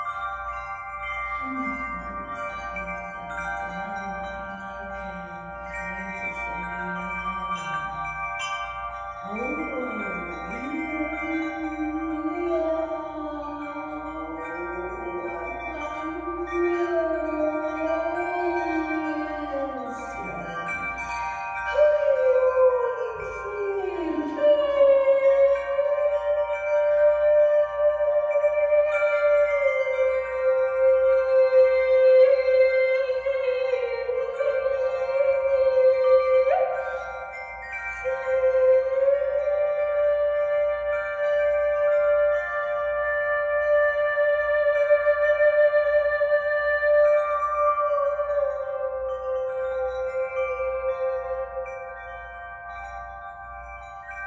Carillons et Voix                    Durée 27:39